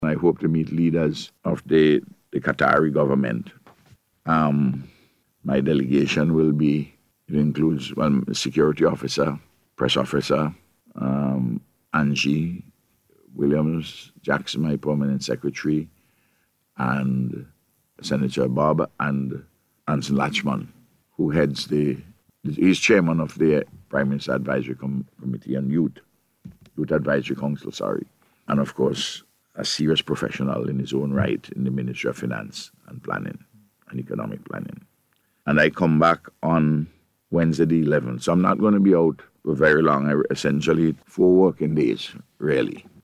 The Prime Minister said on NBC Radio yesterday that he will engage with global leaders on issues that are of importance to St. Vincent and the Grenadines.